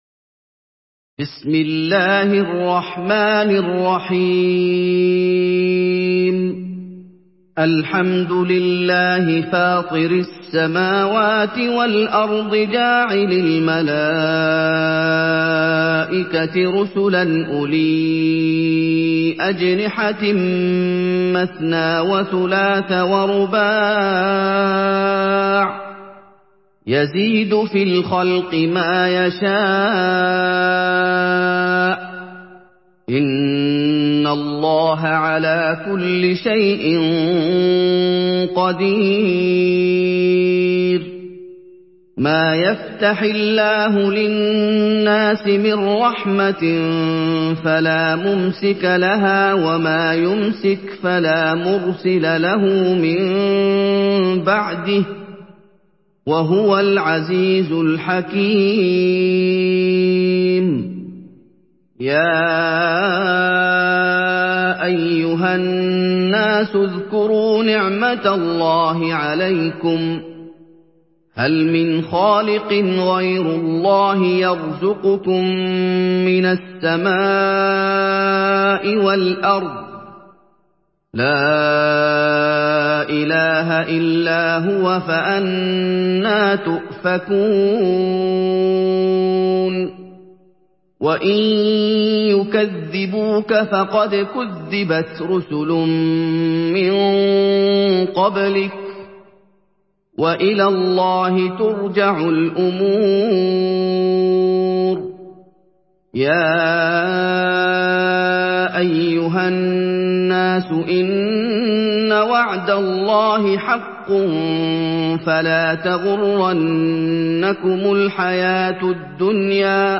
Surah Fatır MP3 by Muhammad Ayoub in Hafs An Asim narration.
Murattal